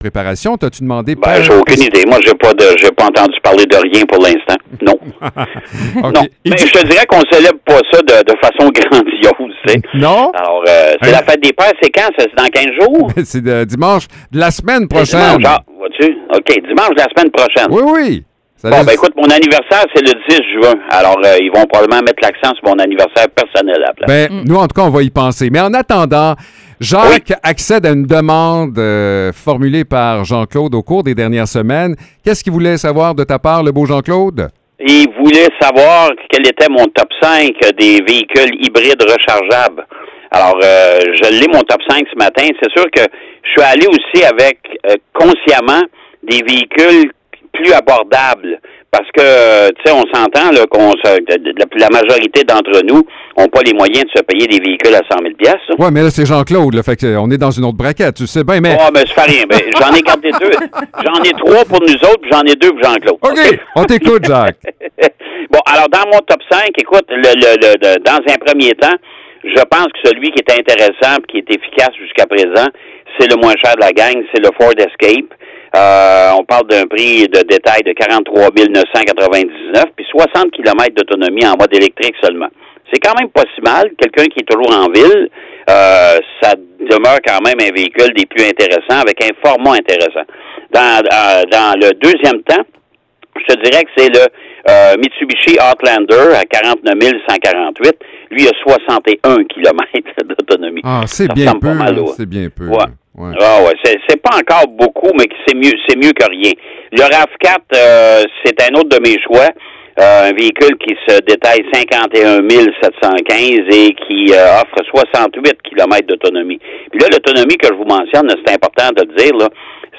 Chronique automobile